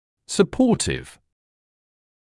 [sə’pɔːtɪv][сэ’поːтив]поддерживающий; вспомогательный, заместительный